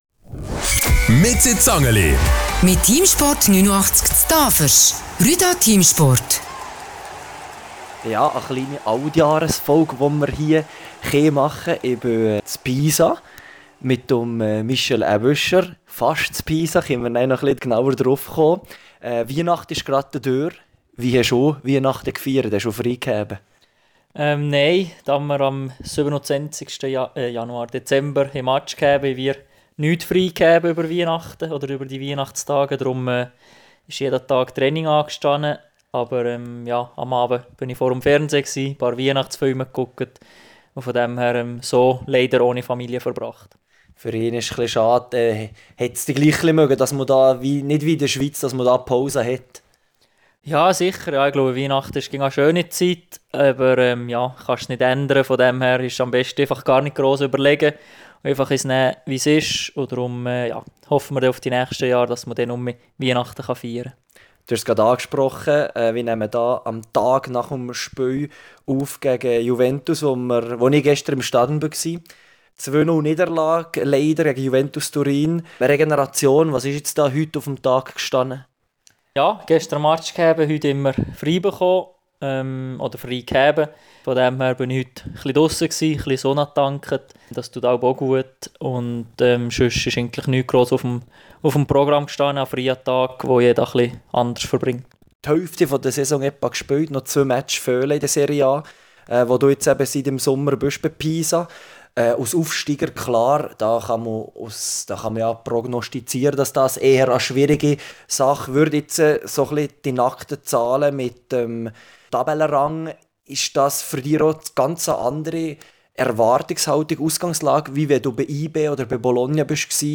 Was sagt der bald 29-jährige Heitenrieder zu Social Media und Geld – und was möchte Michel Aebischer nach seiner Karriere machen? Diese Fragen beantwortet er direkt aus seiner Wohnung in Viareggio am Meer.